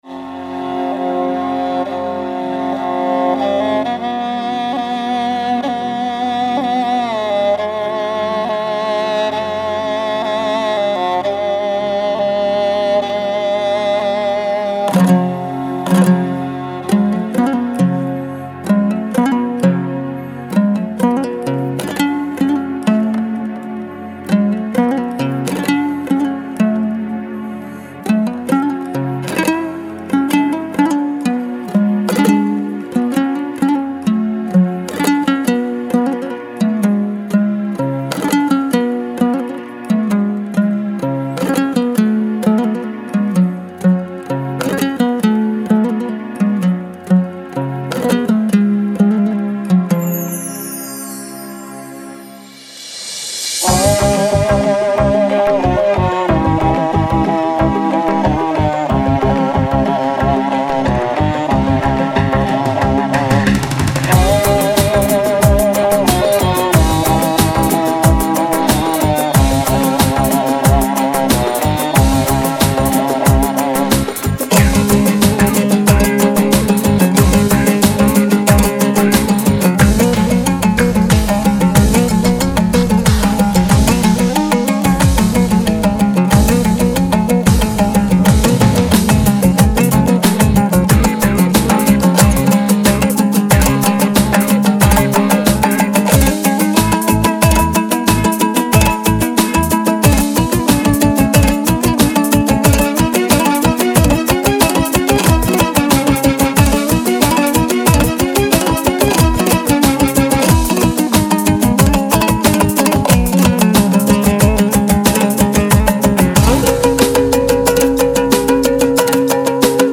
это инструментальная композиция